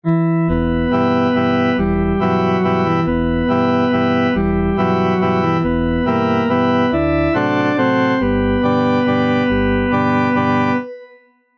Il manque beaucoup d'harmoniques mais la virtualisation de l'instrument respecte ses caractéristiques.
Le résultat est assez satisfaisant bien que ça ressemble à un orgue : 🎧